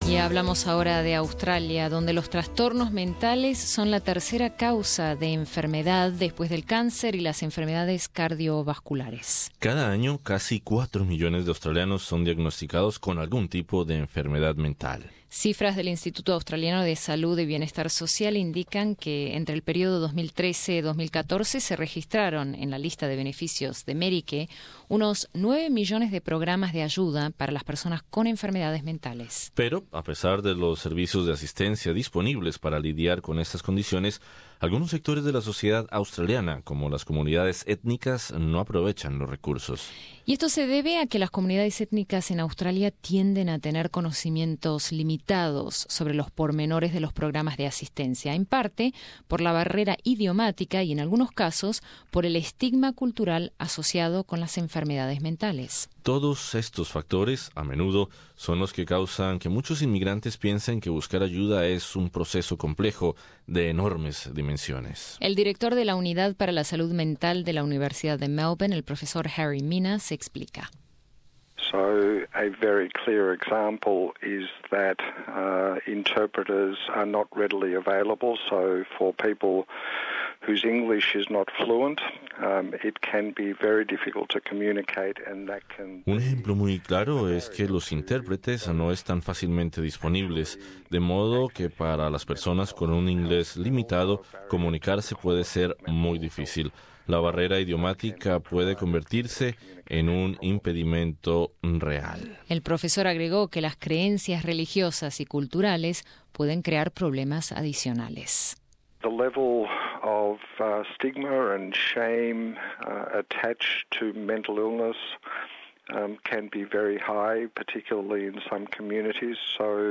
Escucha nuestro informe especial sobre la ayuda disponible en Australia para lidiar con dichas enfermedades.